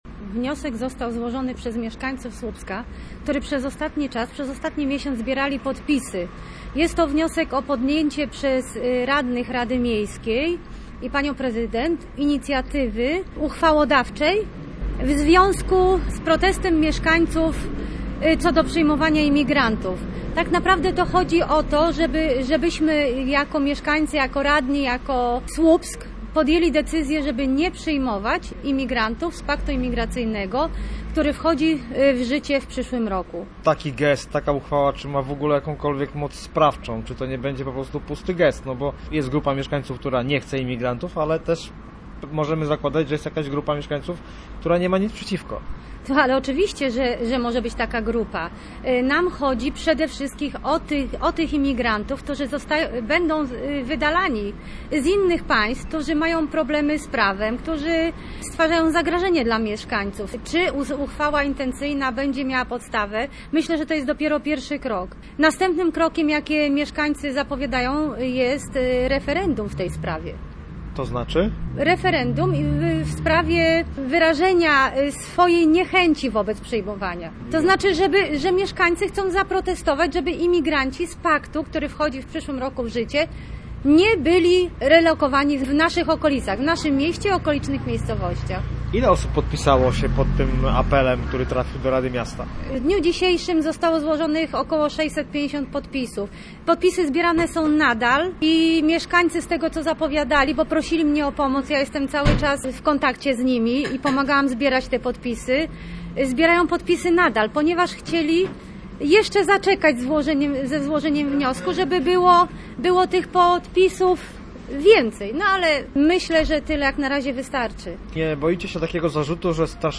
Posłuchaj rozmowy z radną PiS Marzeną Gmińską: https